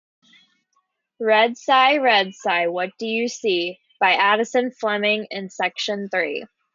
A repetitive, rhythmic chant follows Red Cy as it sees fresh white snow, elevator buttons, and Cy Ride, building to a final cumulative line.